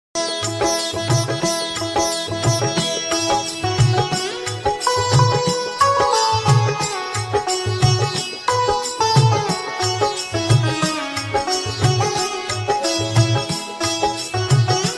Instrumental
soothing and powerful background music